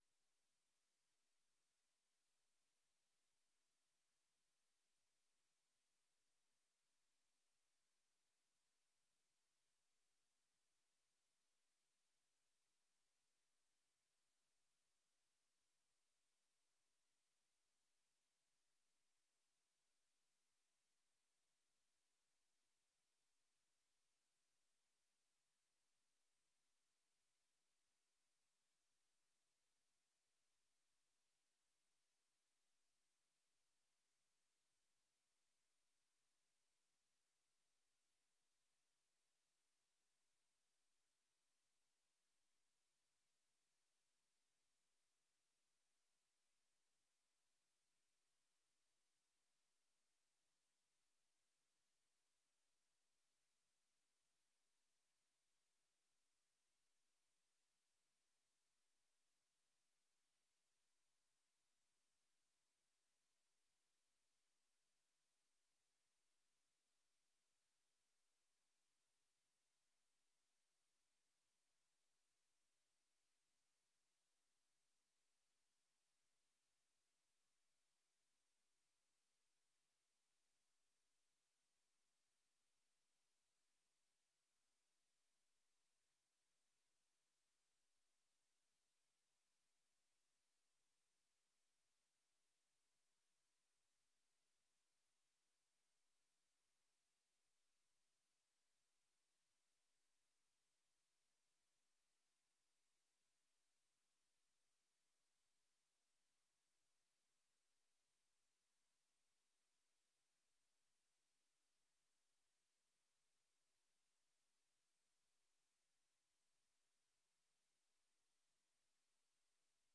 De gemeenteraad heeft op 26 juni 2025 besloten burgemeester Stoop voor te dragen voor herbenoeming voor een tweede termijn als burgemeester van West Betuwe. De Commissaris van de Koning van de provincie Gelderland, de heer Wigboldus, zal tijdens deze vergadering de burgemeester voor zijn tweede termijn beëdigen en de eed afnemen.
Locatie: Raadzaal
Toespraken en dankwoord